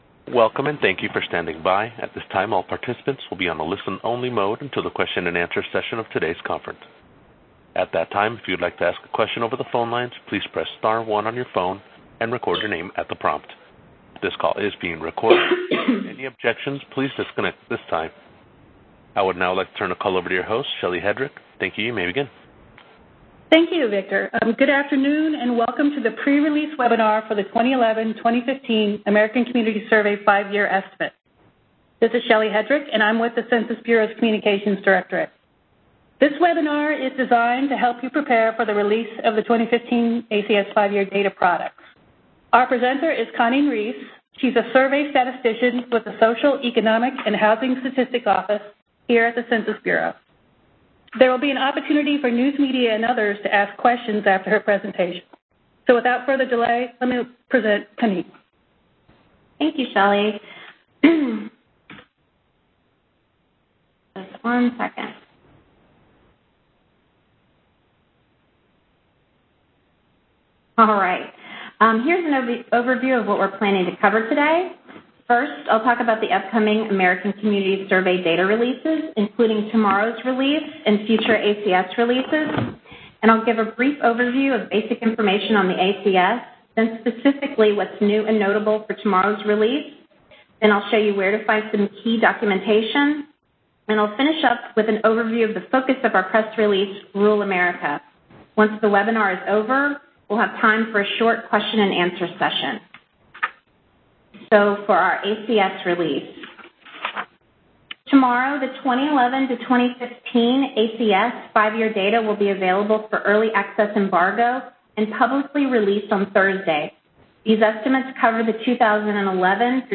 Census Bureau to Host Webinar on Release of 2011-2015 American Community Survey Statistics